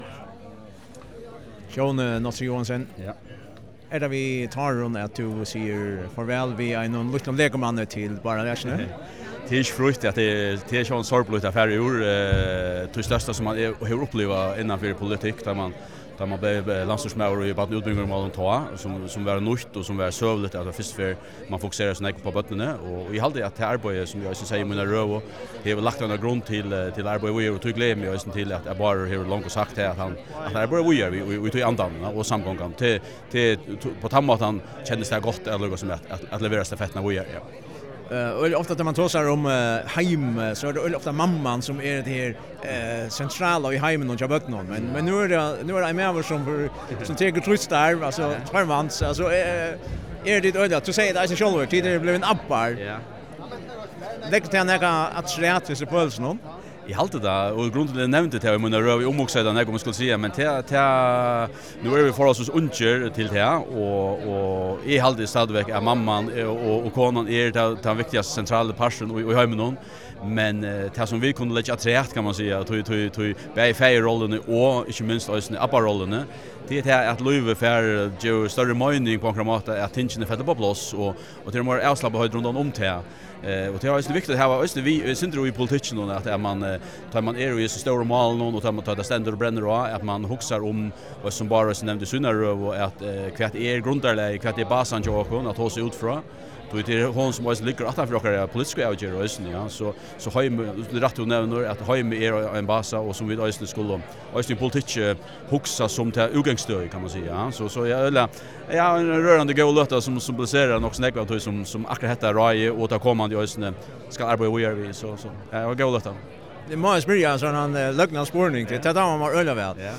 Djóni Nolsøe Joensen er fráfarandi landsstýrismaður og greiðir her eitt sindur frá hvat hansara høvuðsuppgáva hevur verið seinastu tíðina í sessinum sum landsstýrismaður við Barna- og undirvísingarmálum.